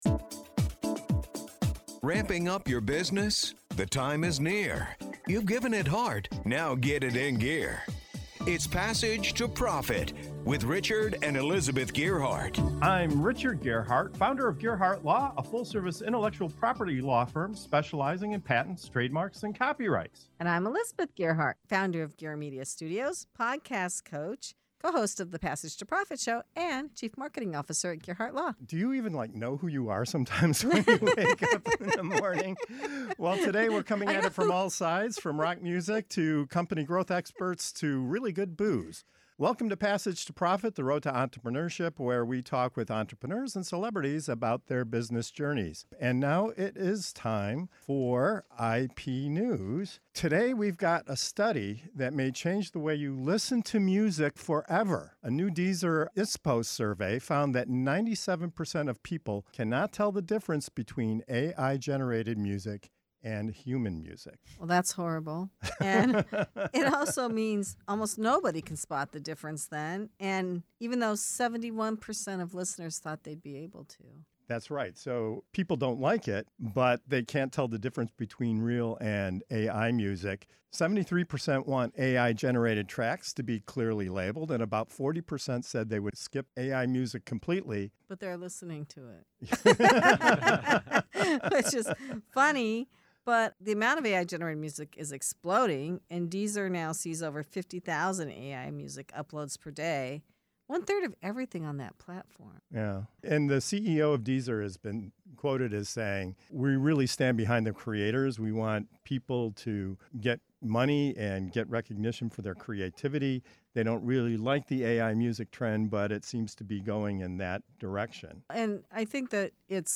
In this segment of "IP News" on Passage to Profit Show, the hosts dive into eye-opening new data showing that 97% of people can’t tell the difference between AI-generated music and human-made tracks, even as many say they dislike it. They explore what this means for artists, listeners, and platforms like Deezer, unpack the growing copyright battles around AI-created music, and explain the real legal risks creators and businesses need to watch out for.